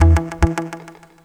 RIFFGTR 05-R.wav